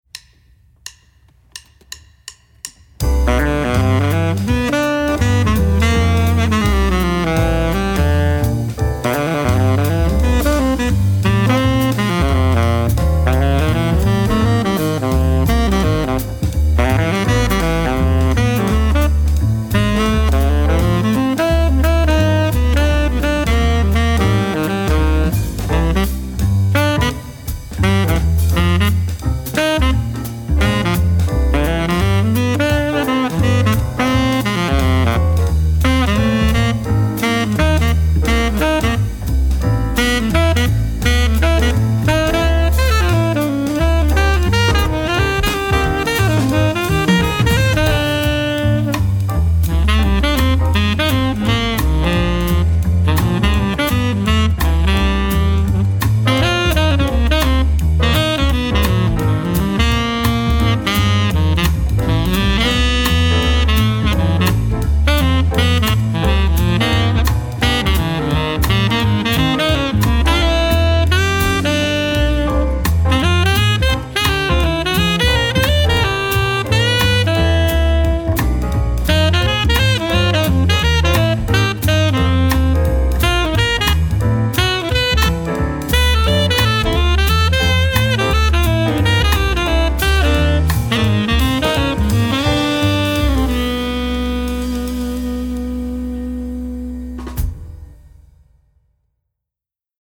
Voicing: T Saxophone Method